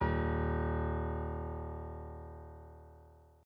SoftPiano